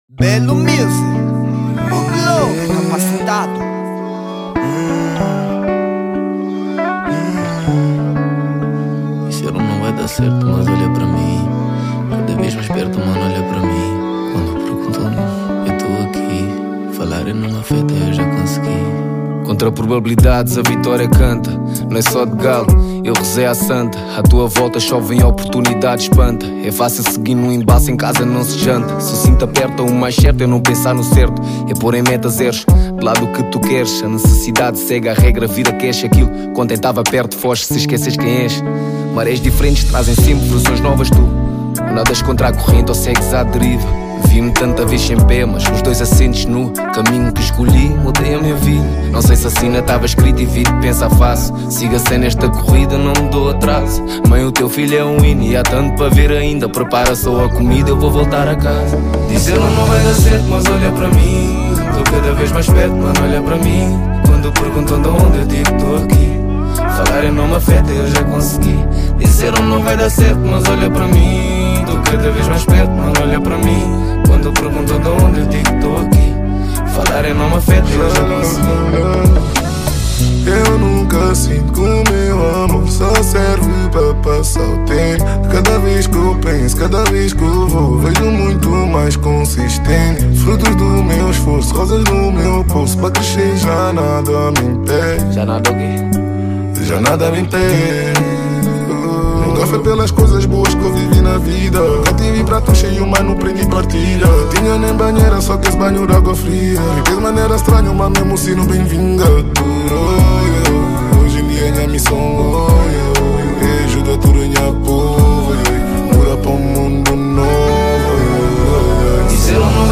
Género : RNB